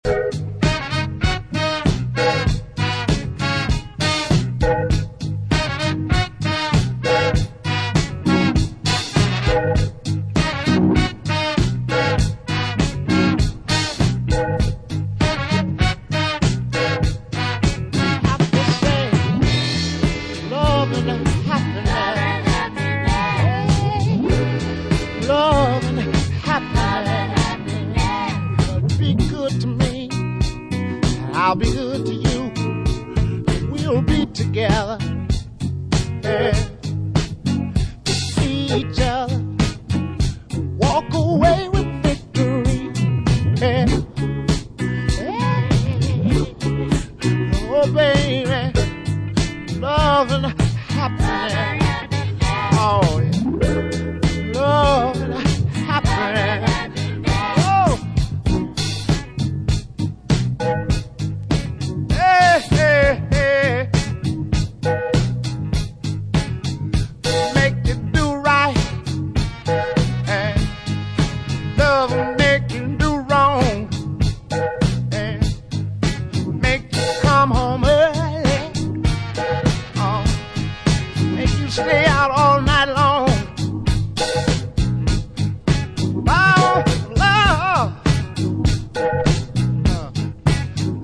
funk / soul classics.
Soul Funk